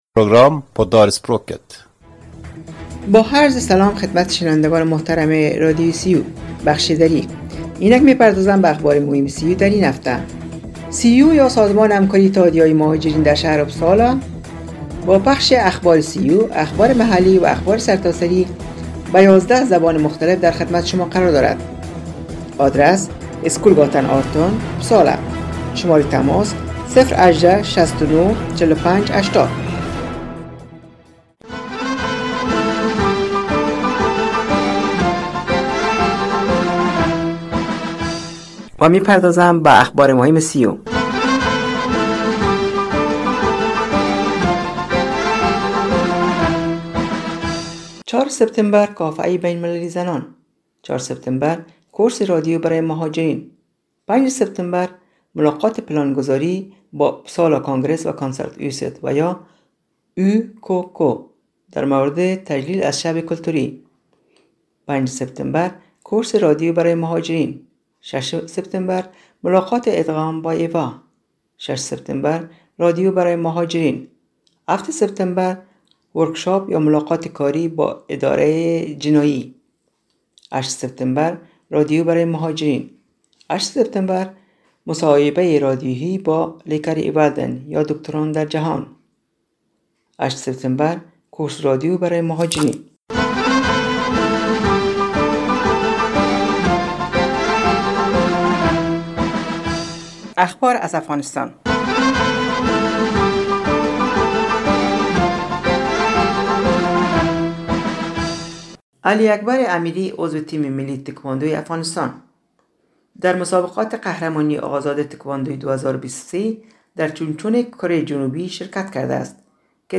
شنوندگان گرامی برنامه دری رادیو ریو یا انترنشنال رادیو در اپسالا سویدن روی موج ۹۸،۹ FM شنبه ها ازساعت ۸:۳۰ تا ۹ شب به وقت سویدن پخش میگردد که شامل اخبار سیو، اخبارمحلی و اخبارسرتاسری میباشد